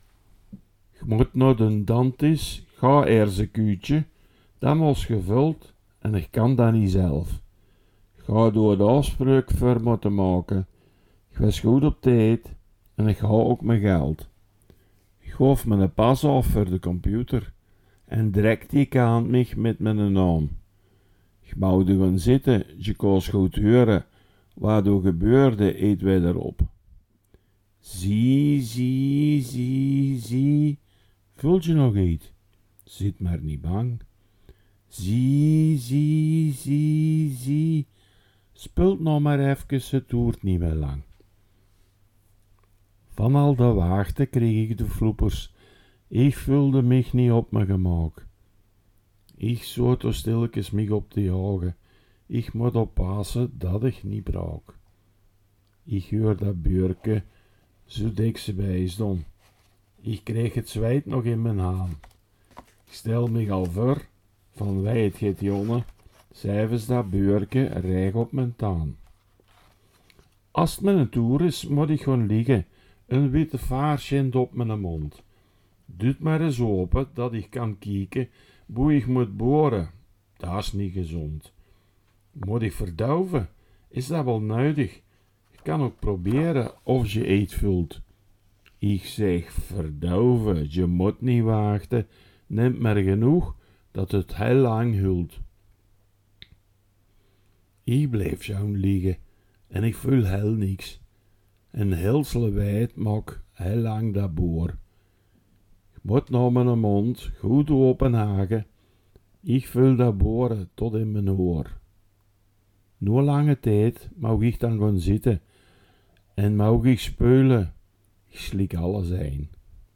Veldeke Belgisch Limburg | Belgisch-Limburgse dialecten